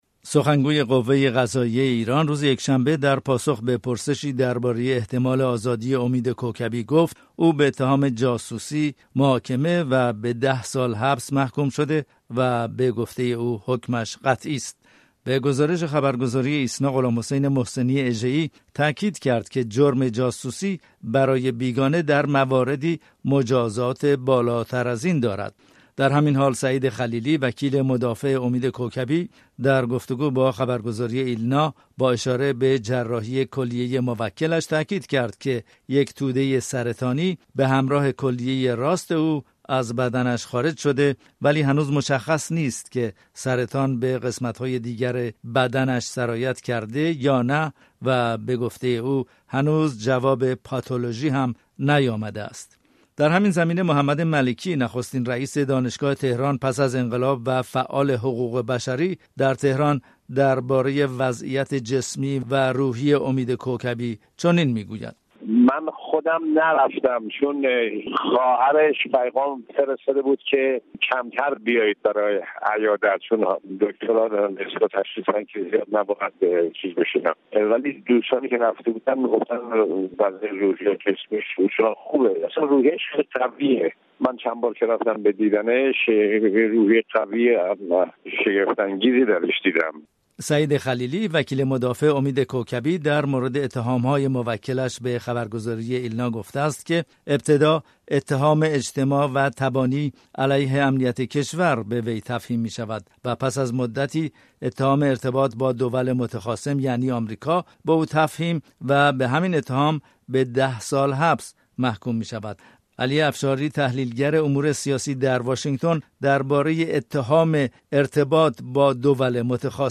گزارش‌های رادیویی